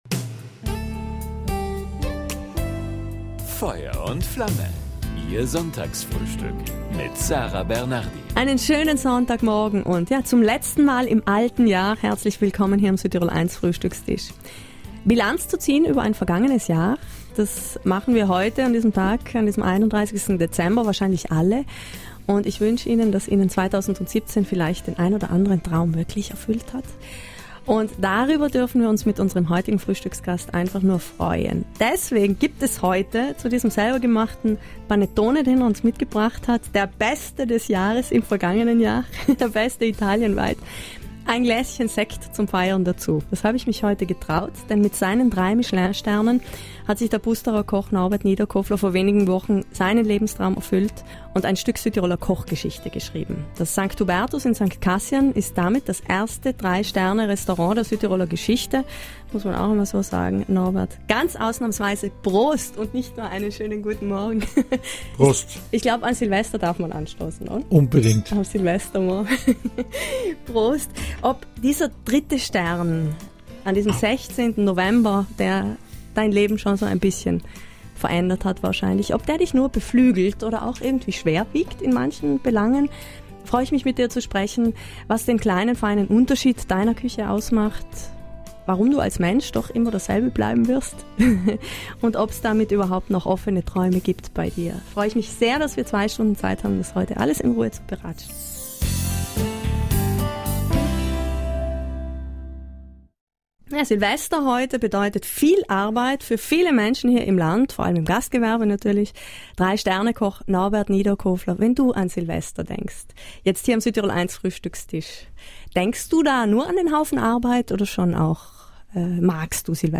Den dritten Stern und seine neuen Träume hat er am Silvestertag zu Gast in „Feuer und Flamme“ mit uns